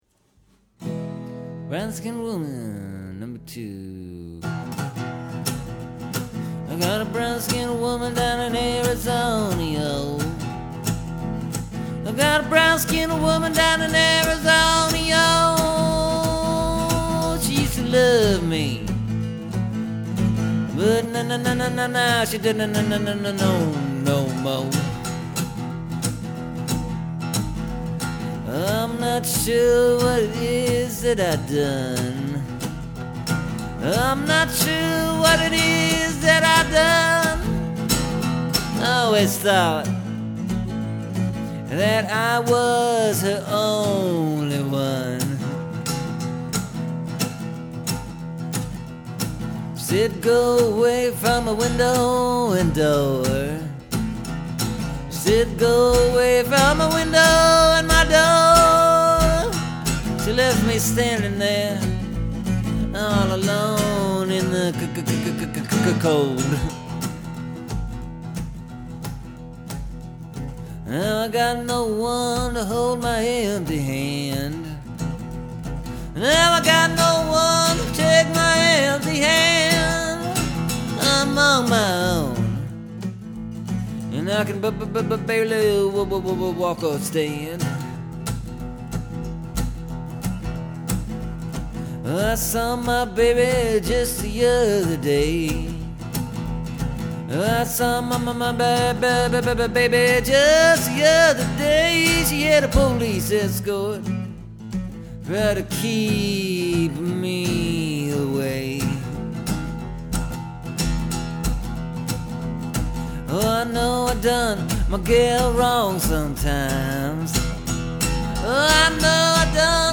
The new recording’s a little different.
I decided to go with the stutter last night. Was doing it all through the whole song at first (it’s something new, so I got excited), but realized that was a little too much of the same thing.